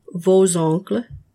When one word ends in an “n” or “s” and is followed by a word that starts with a vowel sound, you must pronounce the “n” as itself and the “s” as a “z”.
Click on each of the following examples of liaison, and repeat the proper pronunciation after the speaker.